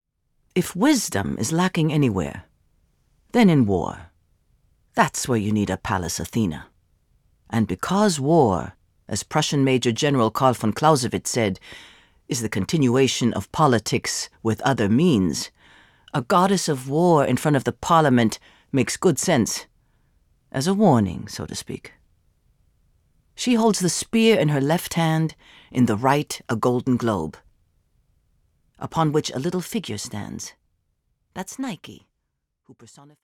Alle 16 Geschichten wurden in Deutsch und in Englisch von den schönsten Stimmen eingelesen.